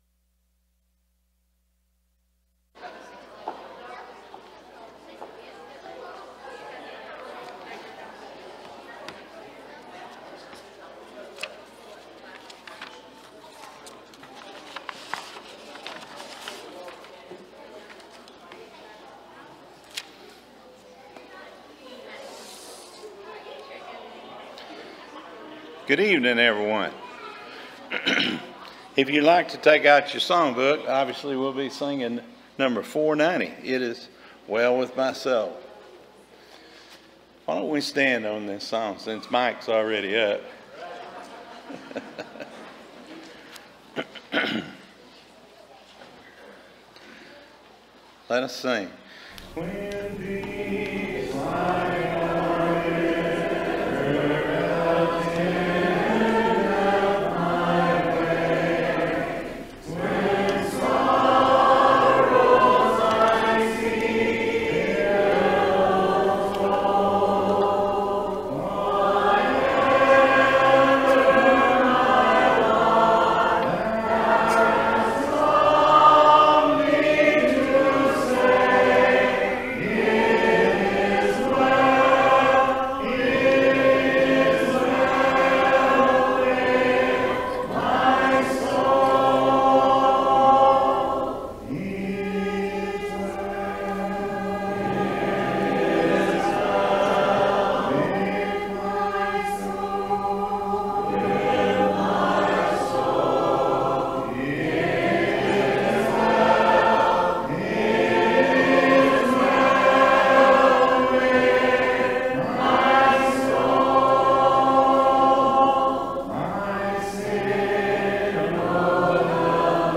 John 14:2, English Standard Version Series: Sunday PM Service